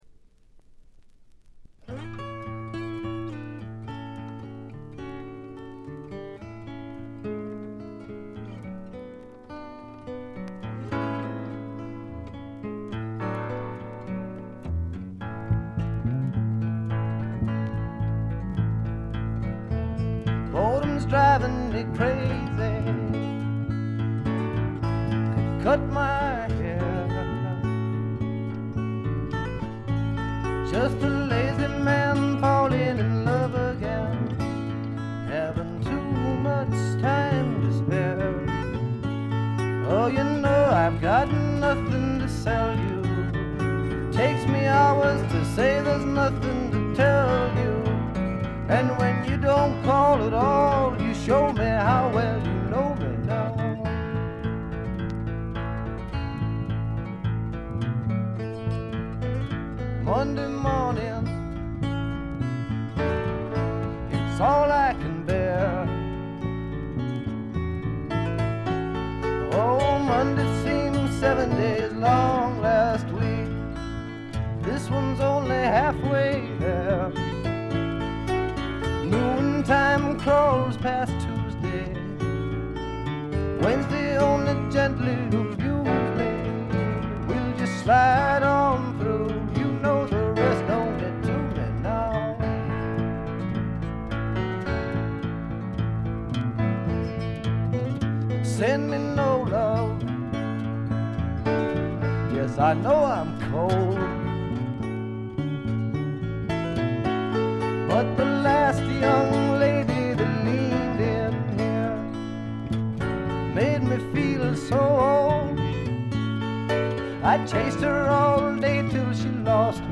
軽微なバックグラウンドノイズ、チリプチ程度。
試聴曲は現品からの取り込み音源です。